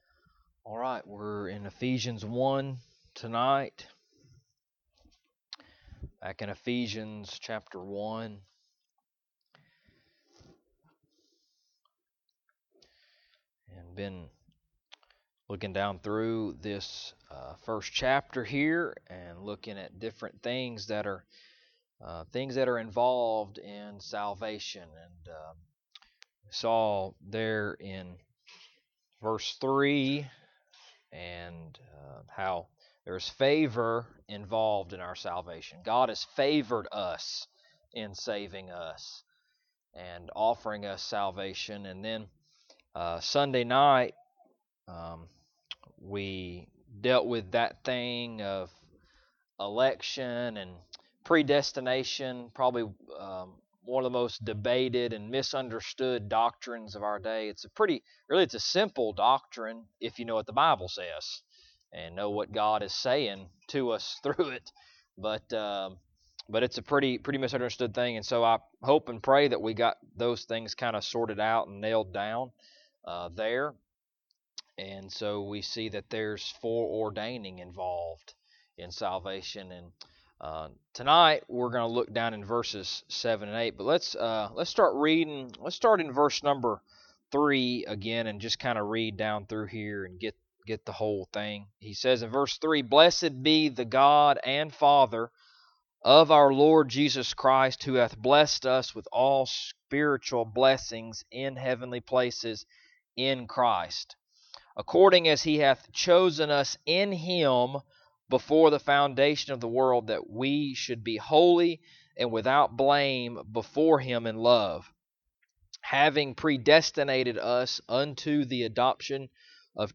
Ephesians Passage: Ephesians 1:3-8 Service Type: Wednesday Evening Topics